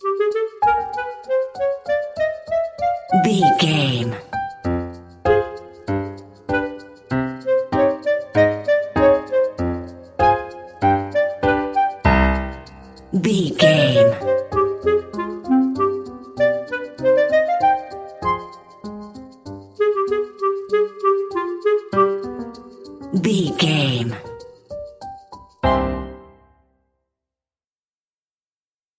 Uplifting
Aeolian/Minor
flute
oboe
strings
orchestra
cello
double bass
percussion
silly
goofy
cheerful
perky
Light hearted
quirky